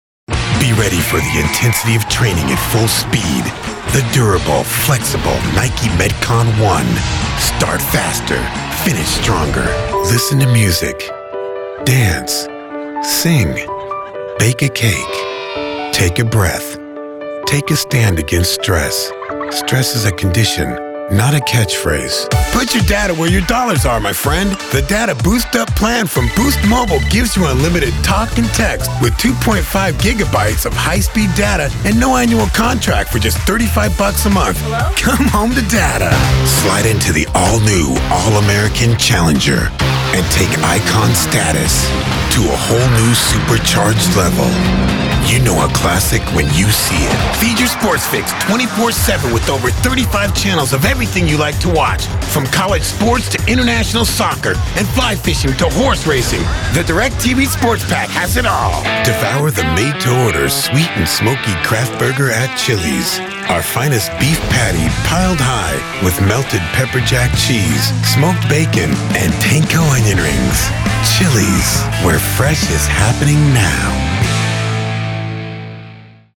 Mature Adult, Adult, Young Adult
Accents: southern us southern us standard us
commercial
bloke cool warm